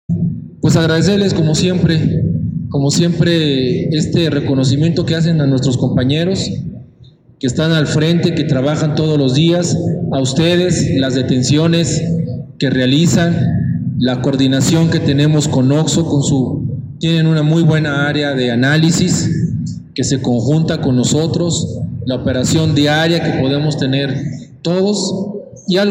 Ricardo Benavides Hernández, secretario de Seguridad Ciudadana destacó que, el reconocimiento que realiza el sector privado al personal de la corporación, los motiva desempeñar de la mejor manera ante las necesidades de la ciudadanía.